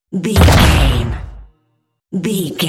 Dramatic hit door slam
Sound Effects
heavy
intense
dark
aggressive
hits